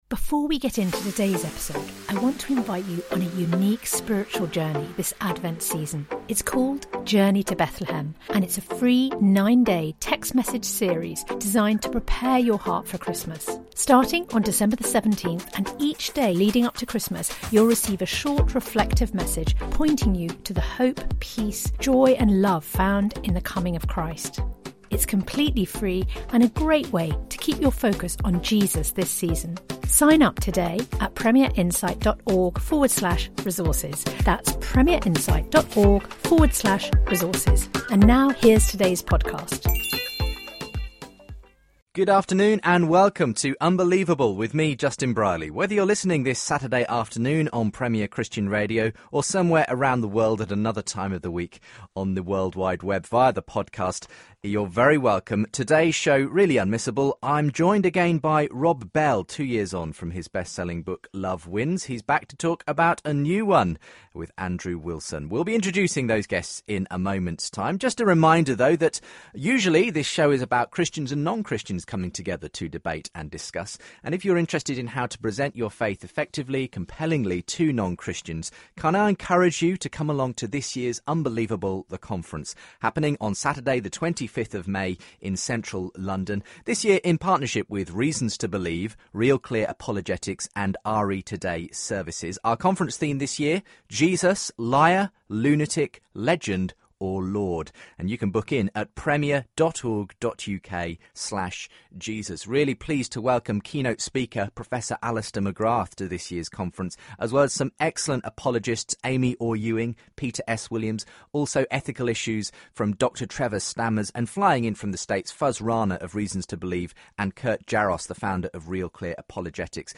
Rob Bell debates God, Salvation & Homosexuality - Unbelievable?